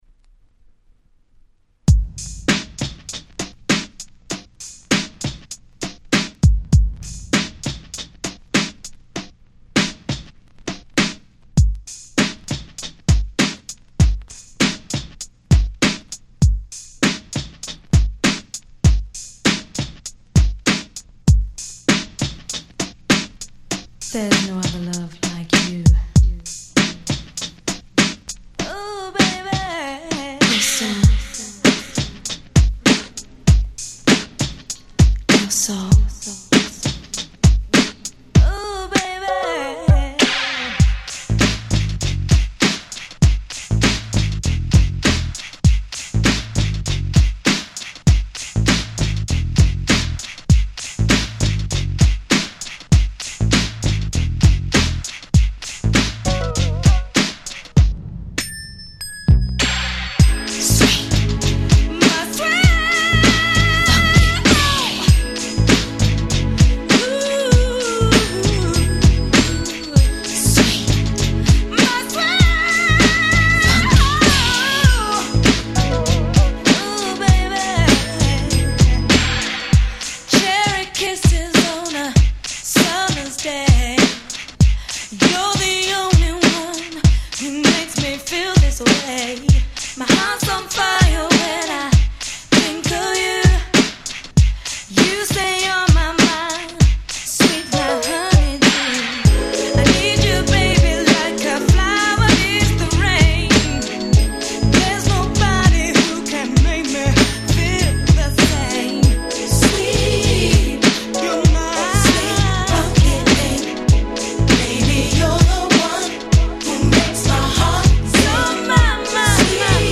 【Media】Vinyl 12'' Single
派手さは無いですがシンプルで固いBeatに彼女達のSmoothなVocalが映える、まさに『玄人受け』な1曲。